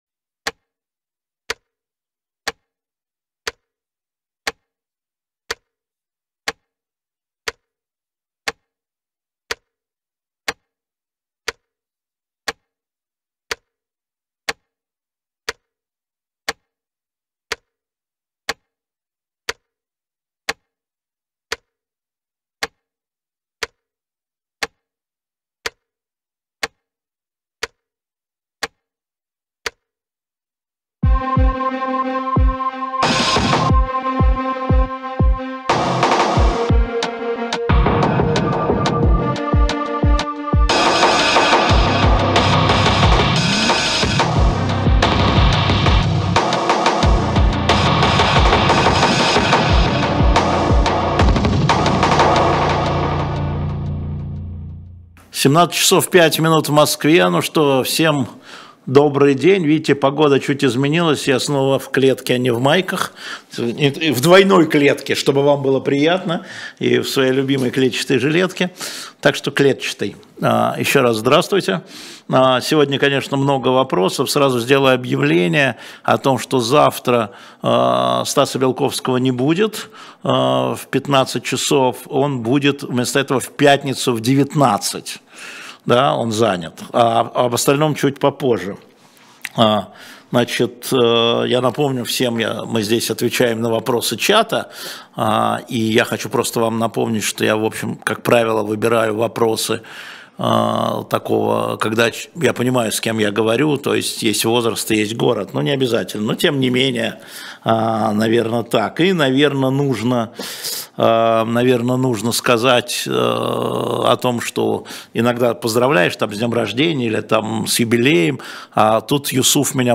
На ваши вопросы в прямом эфире отвечает Алексей Венедиктов.